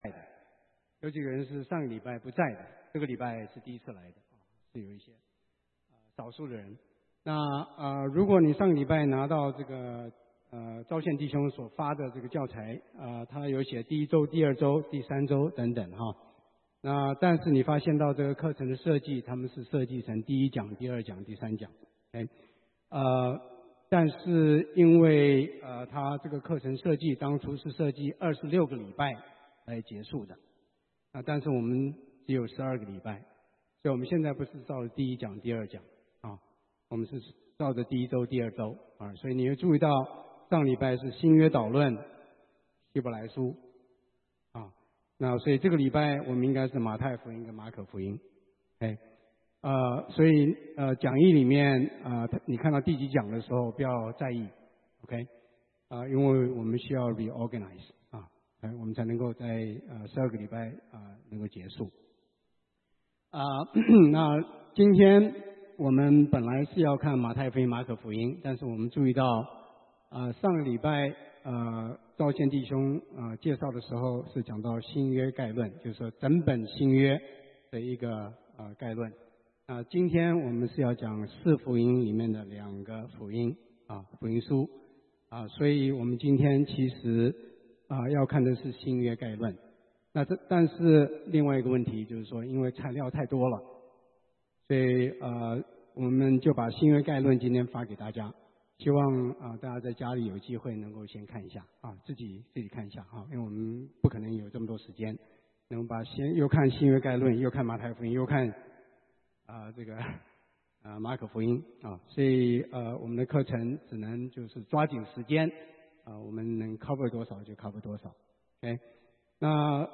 證道: 為何只有一位回來？Why only one came back?